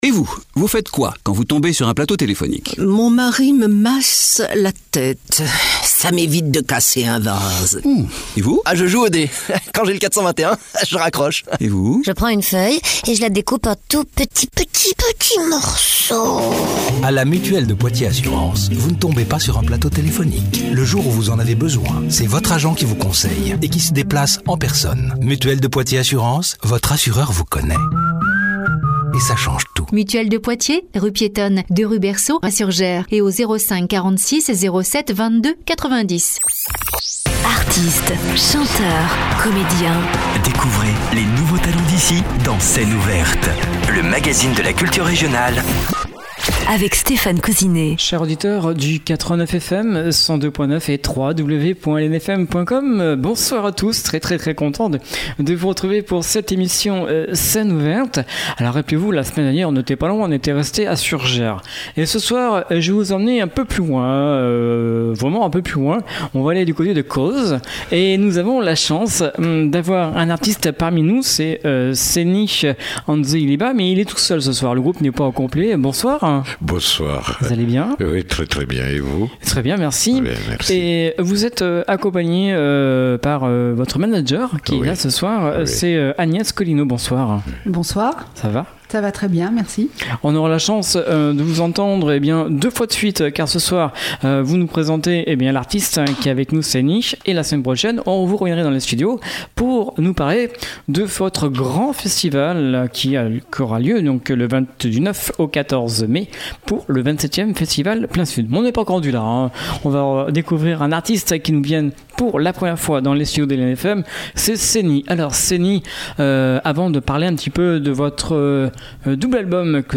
en acoustique
se définit lui-même comme un groupe de Reggae Mandingue.
Balafon
le Yankadi est un rythme traditionnel de Guinée